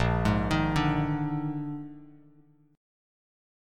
A#11 chord